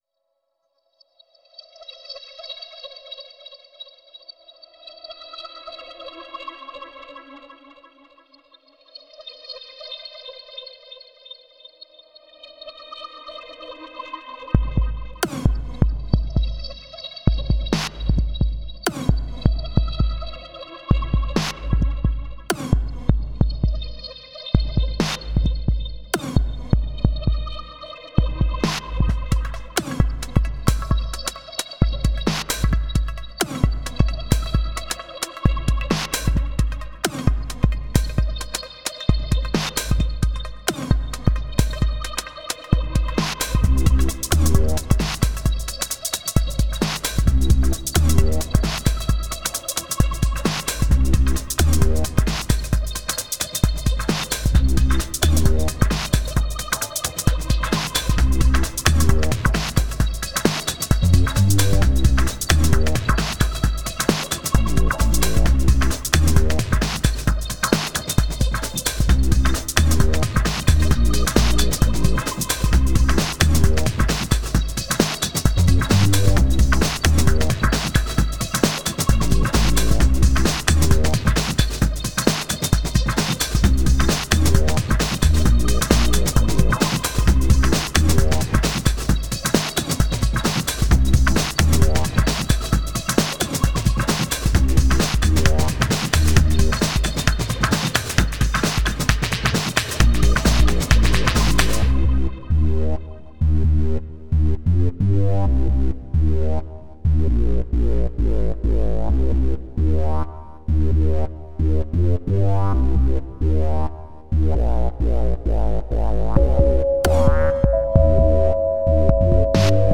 Dirty beats/electronica